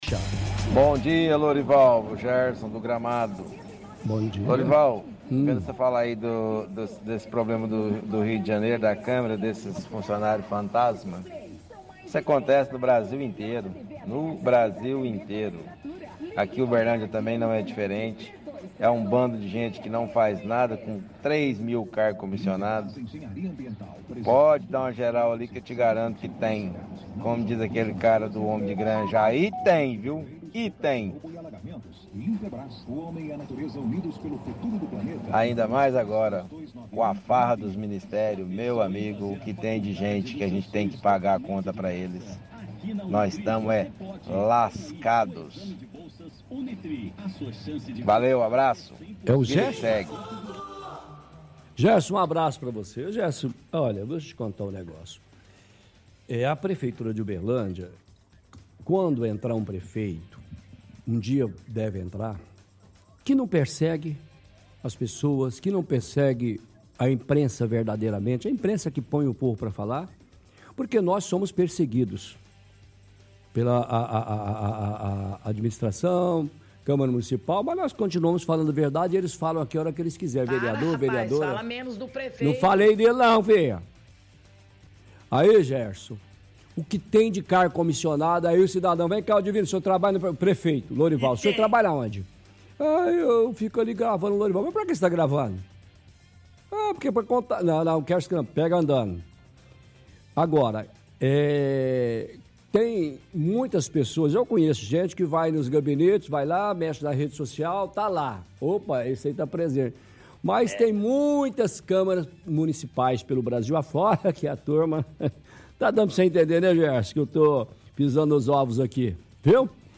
– Ouvinte cita caso de funcionários fantasmas na câmara do Rio de Janeiro e afirma que em Uberlândia acontece a mesma coisa pois são 3 mil cargos.